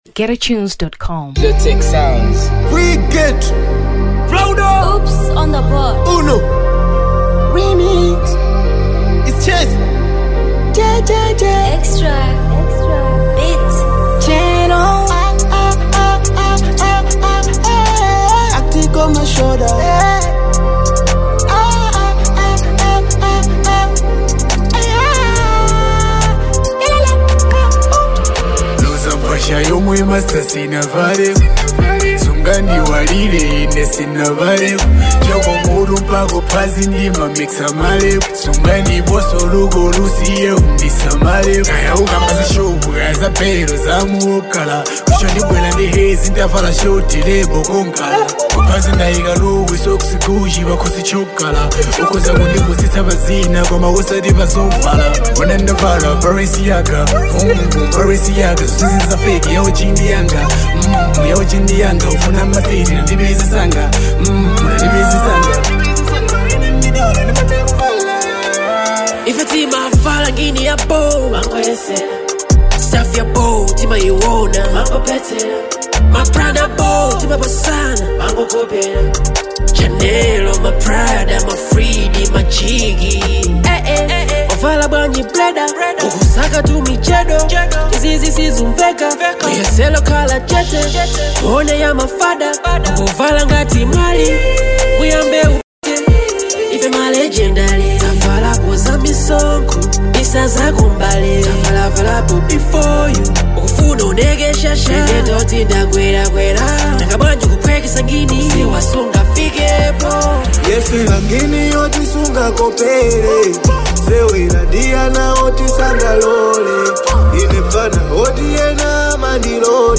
Drill 2023 Malawi